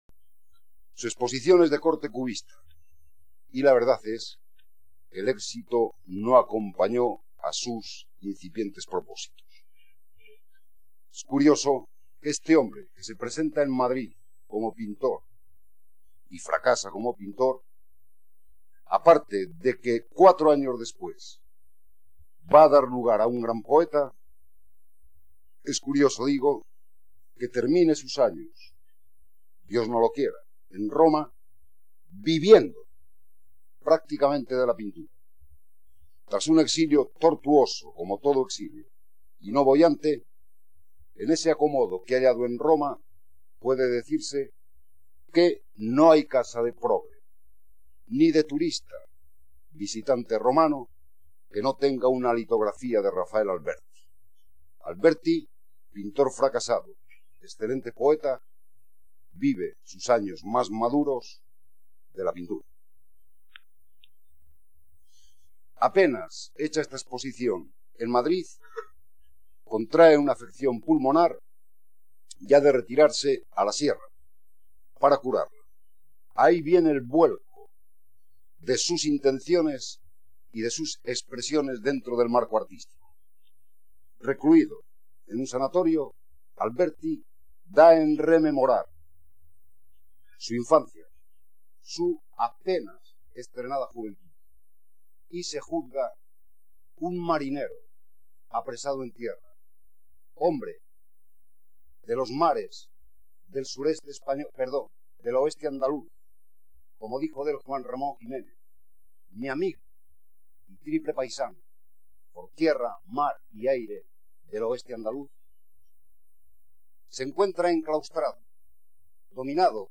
CONFERENCIAS